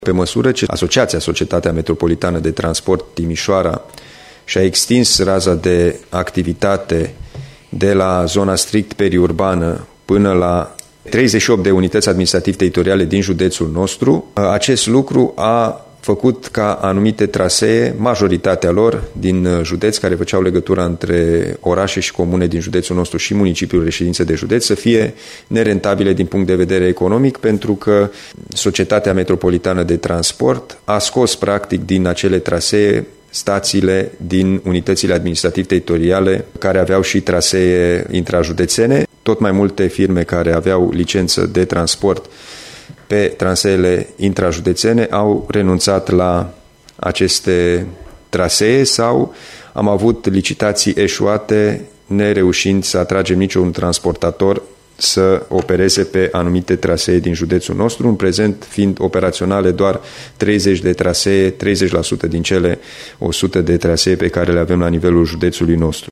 Președintele Consiliului Județean Timiș, Alin Nica, spune că pe măsură ce raya de acoperire a Societății de Transport Metropolitan s-a extins, interesul firmelor de transport de a opera curse interjudețene a scăzut: